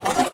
Select Scifi Tab 11.wav